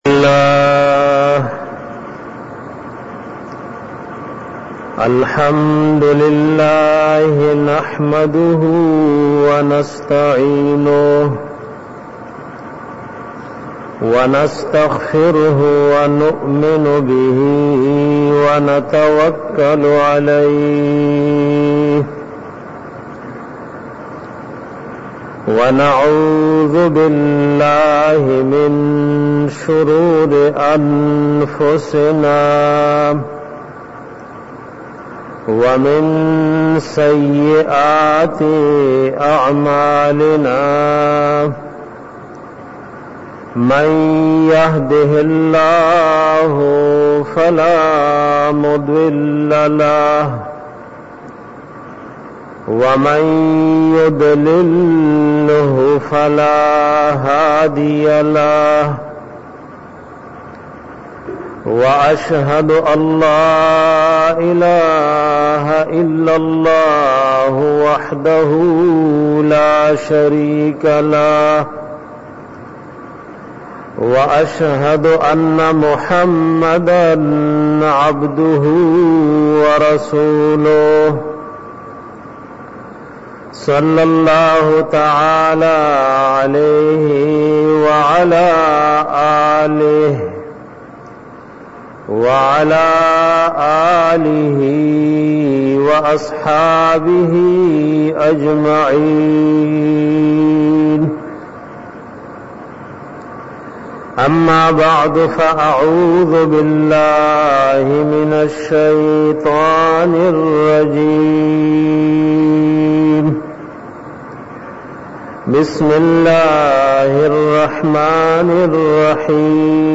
bayan pa bara da fazilat da qurani pak k 6